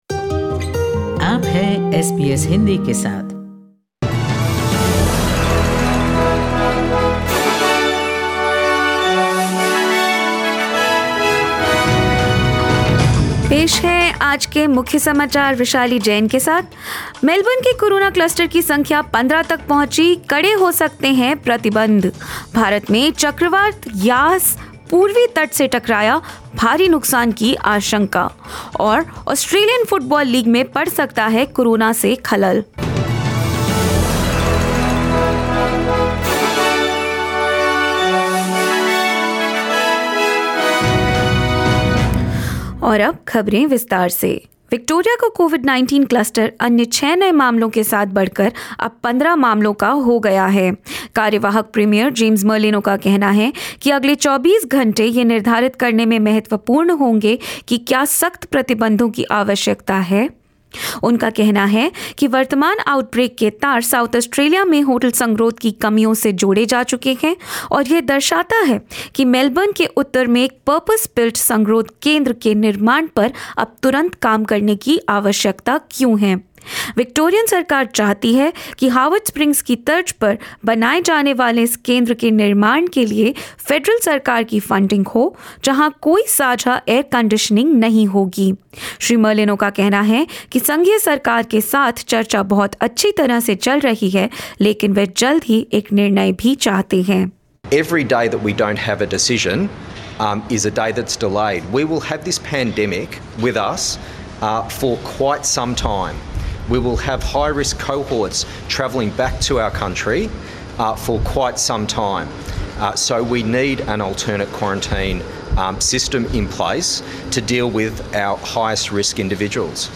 In this latest SBS Hindi News bulletin of Australia and India: Victoria government may impose stricter restrictions as the coronavirus cluster in Melbourne grows to 15; Cyclone Yaas makes landfall in India and more. 26/05/2021